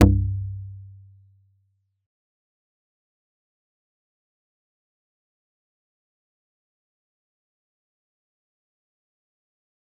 G_Kalimba-C2-mf.wav